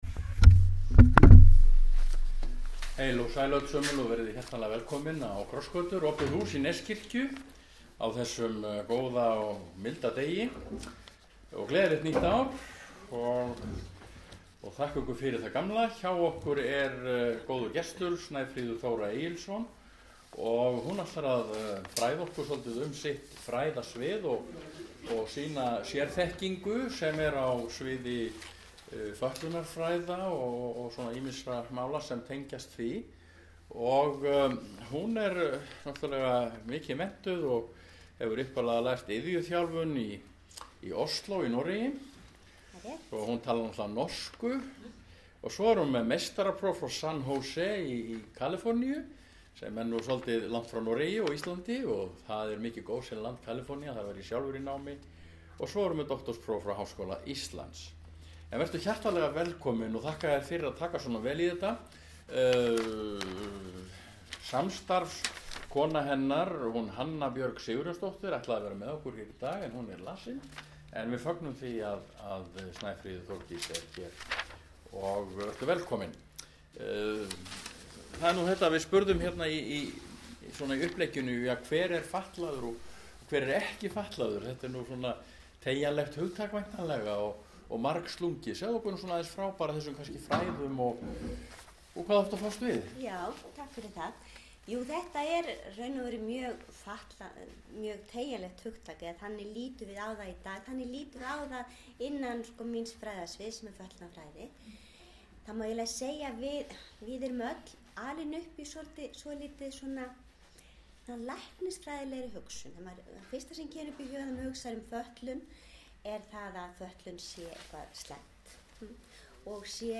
Samtal um fötlunarfræði.